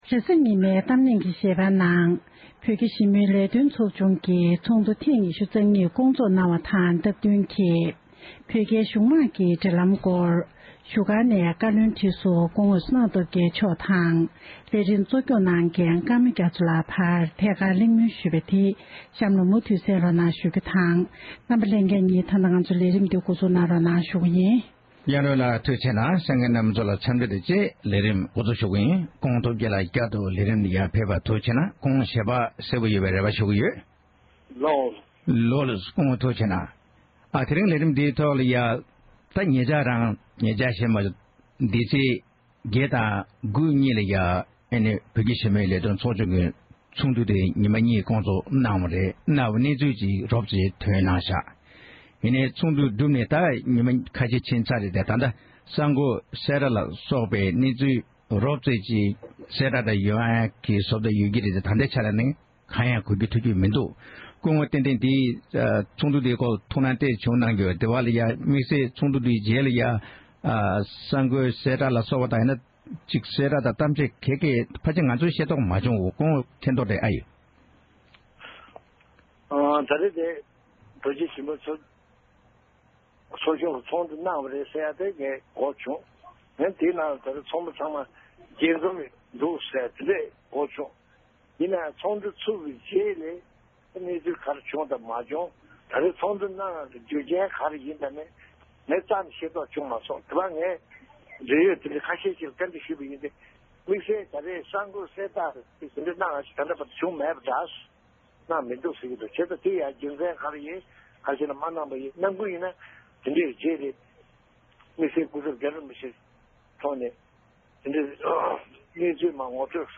གླེང་མོལ་གནང་བ་ཞིག་གསན་རོགས༎